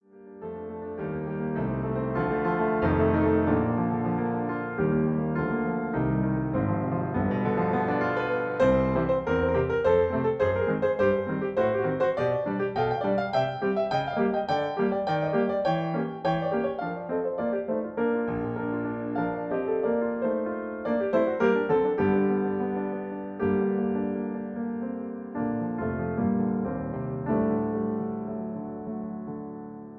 MP3 piano accompaniment